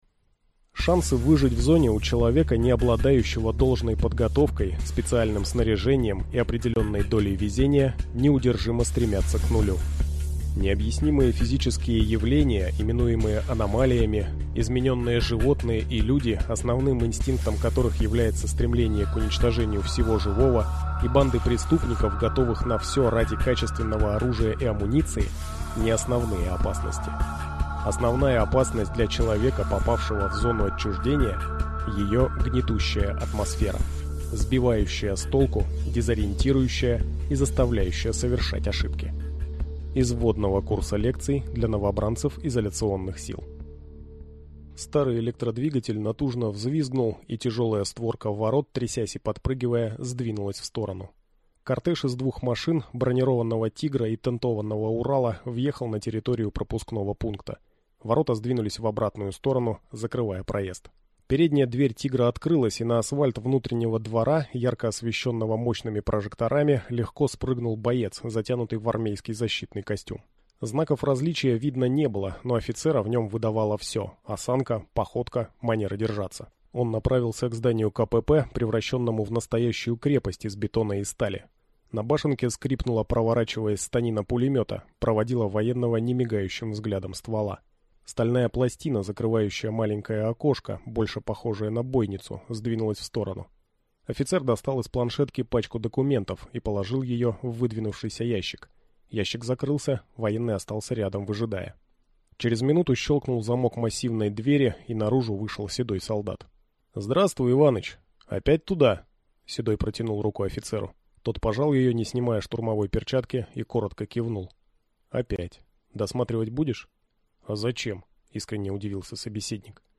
Аудиокнига Взломать Зону. Хакер | Библиотека аудиокниг
Прослушать и бесплатно скачать фрагмент аудиокниги